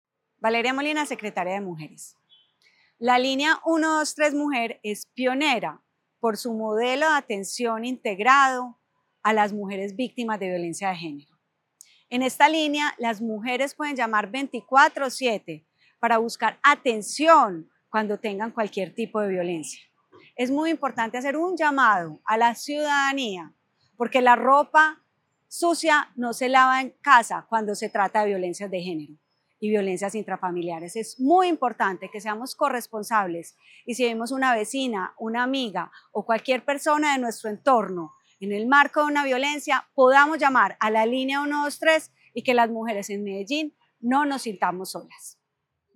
Declaraciones de la secretaria de las Mujeres, Valeria Molina Durante el primer semestre de 2025, la Línea 123 Agencia Mujer ha acompañado a 2.474 mujeres víctimas o en riesgo de violencias basadas en género.
Declaraciones-de-la-secretaria-de-las-Mujeres-Valeria-Molina-1.mp3